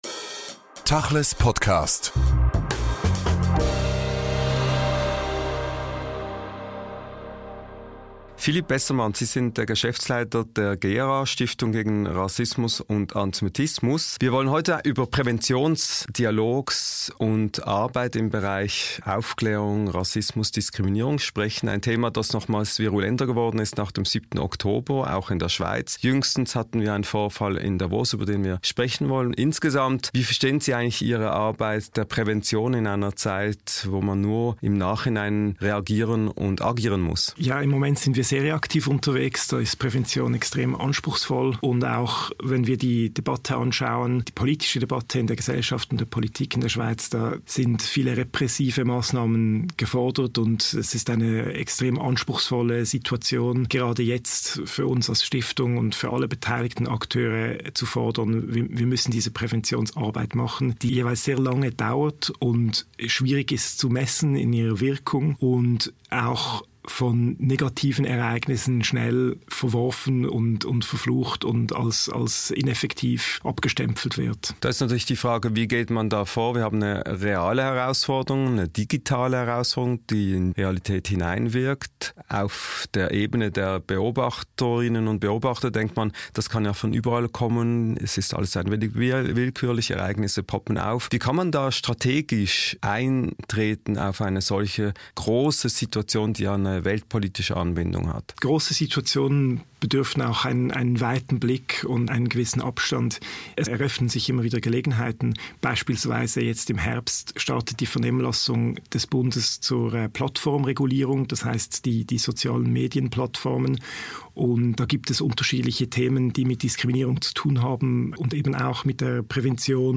im Gespräch über Prävention und Wirksamkeit.